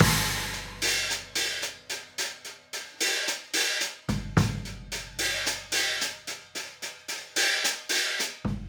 Hi Hat and Kick 05.wav